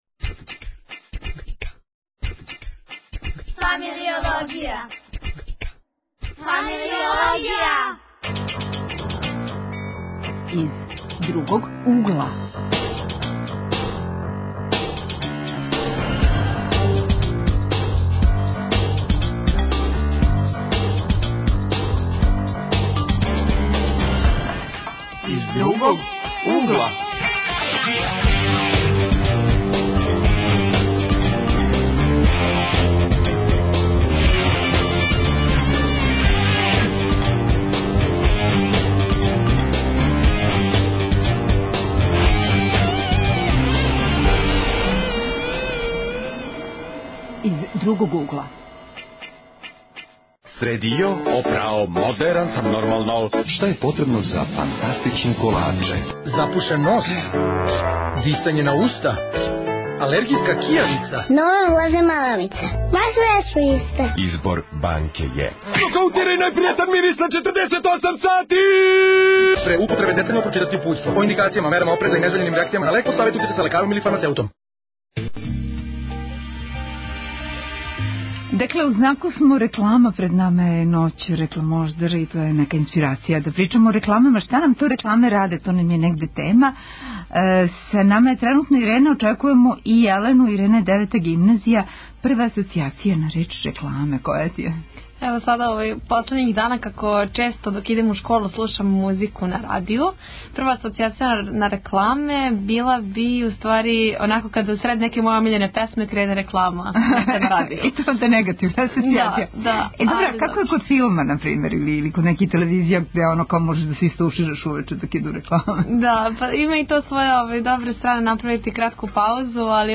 Тема: утицај реклама на свест и подсвест тинејџера (посебан акценат стављамо на рекламе за пиво). Гости - млади који уживају у рекламама и стварају их.